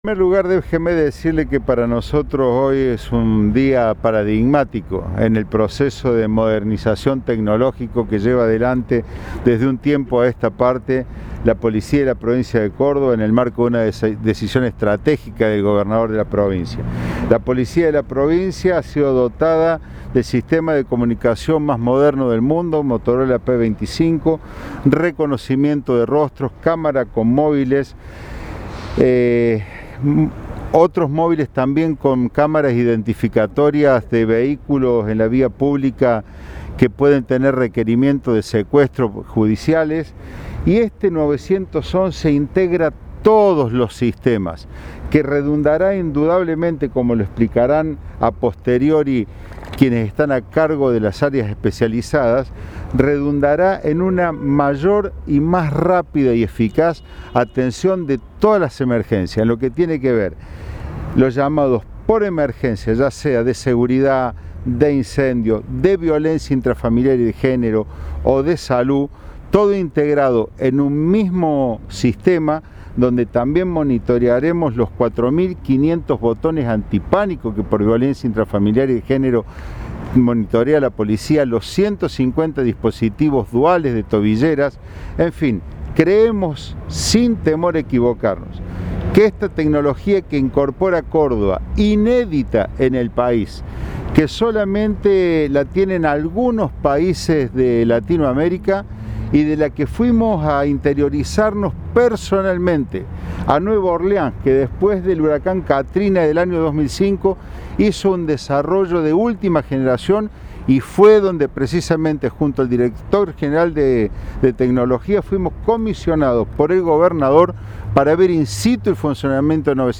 Audio: Alfonso Mosquera (Ministro de Seguridad de la Provincia de Córdoba).